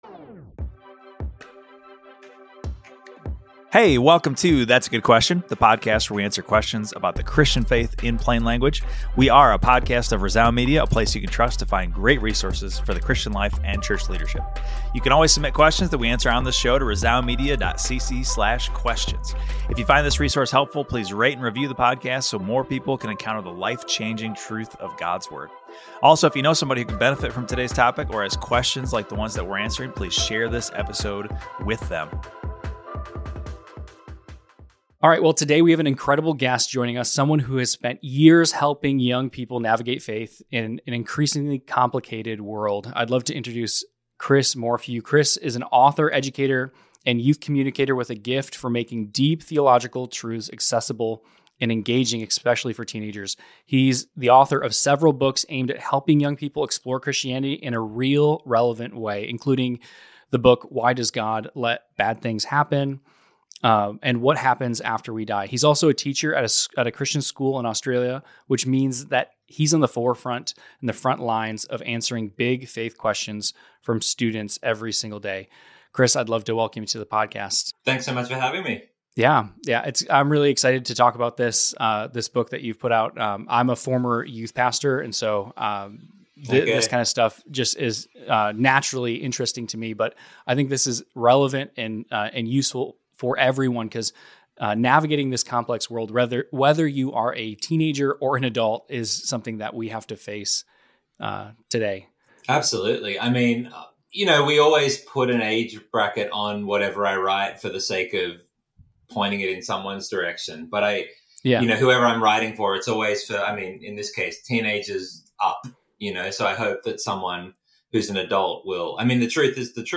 When Life is a Mess, is Jesus Enough? A Conversation